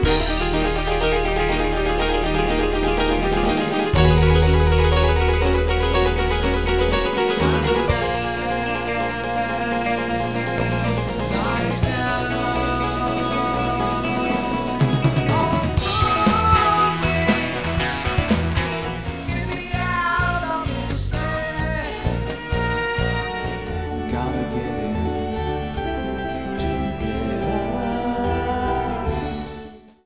Keyboards, backing vocals
30 sec radio mix
art-rock band